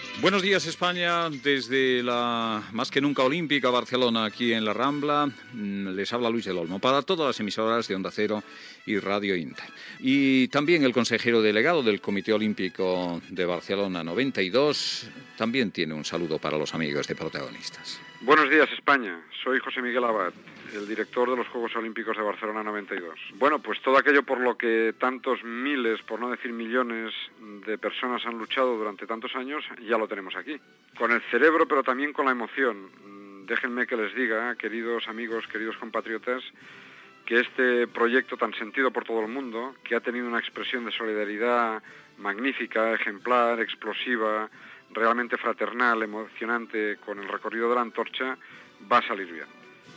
Inici del programa i salutació del conseller delegat del comitè olímpic de Barcelona 92, José Miguel Abad.
Info-entreteniment